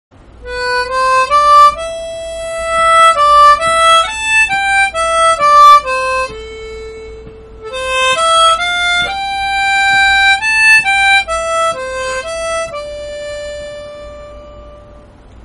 《フリスチューンβ》だと、スキヤキソングのサビ部分は例外として、その他はベンドがひとつもありません。